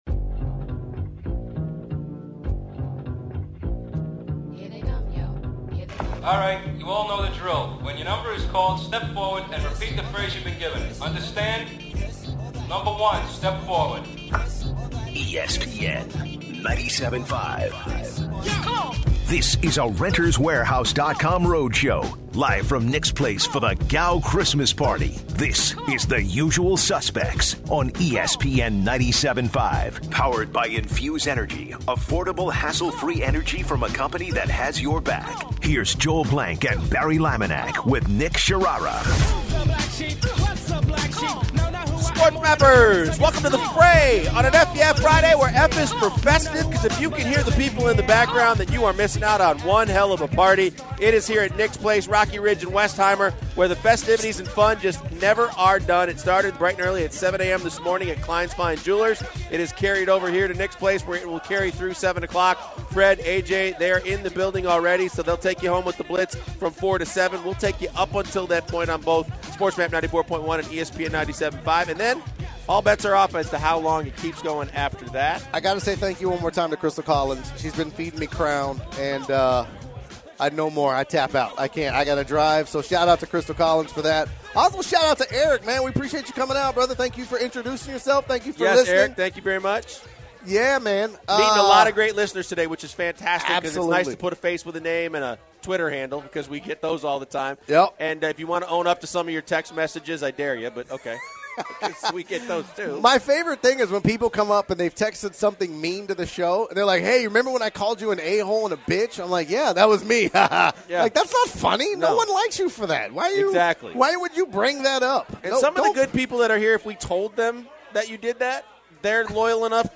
At the start of the final hour, the crew is still raging at Nick’s Place with all of their fans.